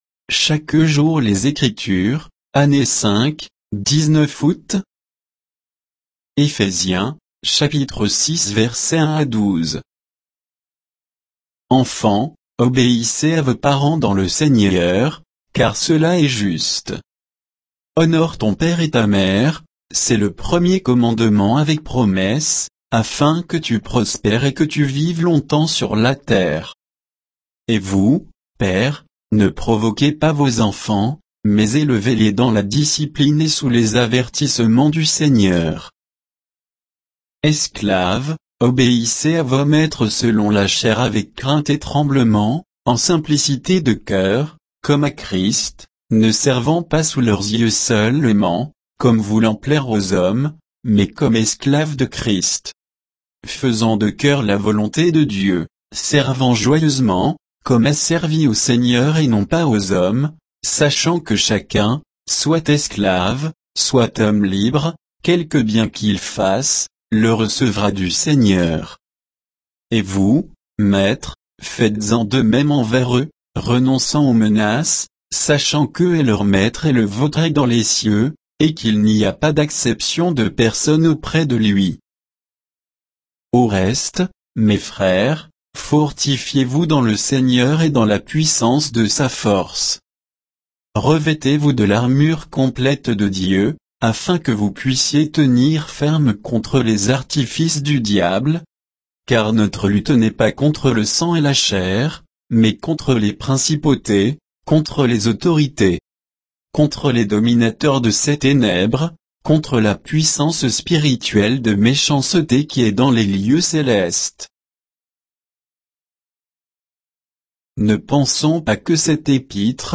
Méditation quoditienne de Chaque jour les Écritures sur Éphésiens 6, 1 à 12